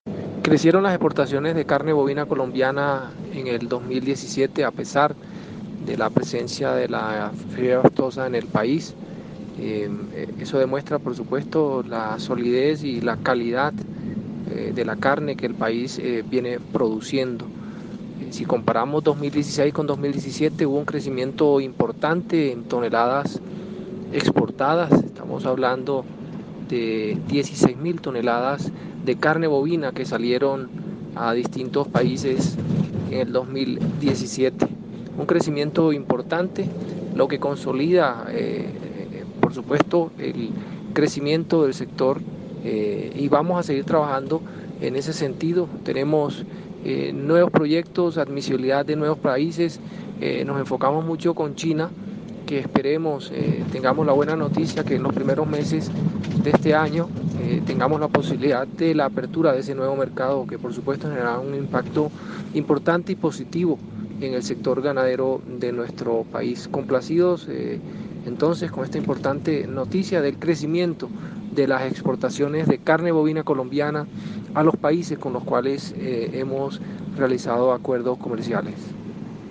Luis_Humberto_Martinez_Gerente_General_ICA_EXPORTA.mp3